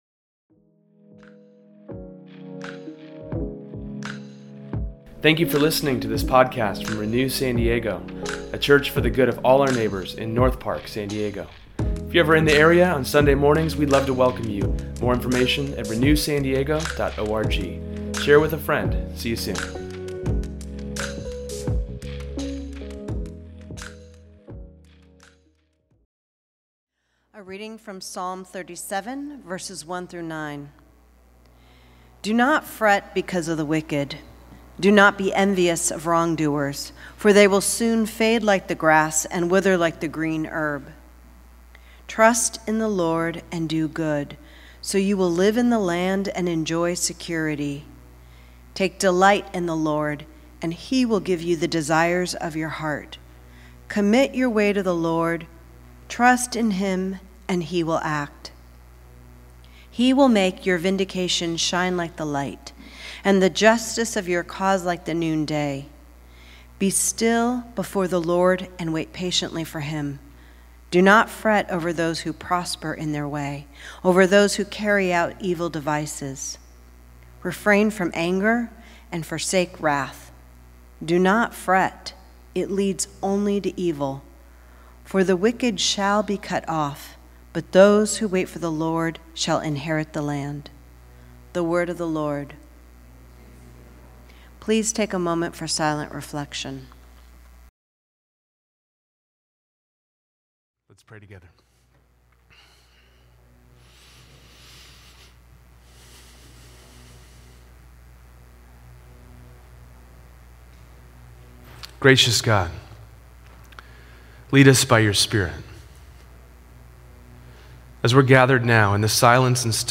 In today’s sermon, we look at what hope looks like, and visit 4 reasons we fret: economic anxiety, political corruption and division, violence and war, and the fragility of life.